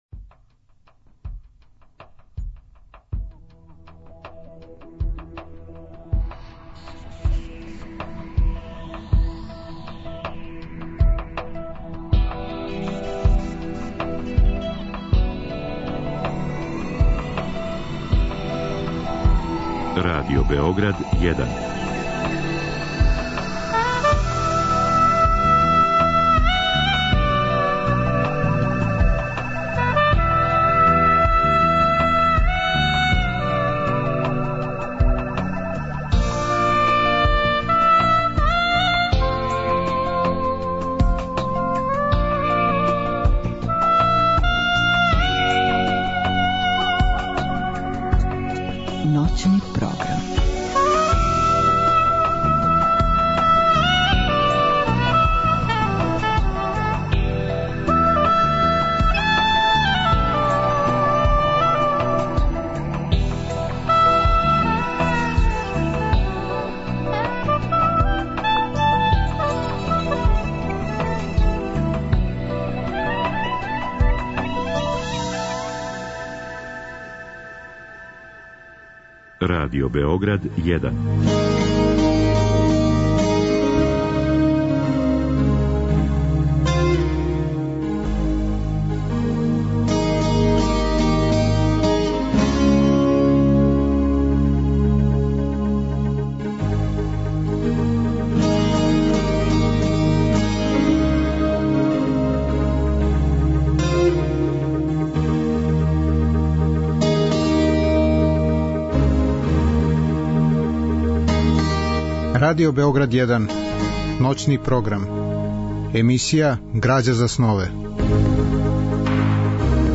Разговор и добра музика требало би да кроз ову емисију и сами постану грађа за снове.
Радио-драма је реализована у продукцији Драмског програма Радио Београда.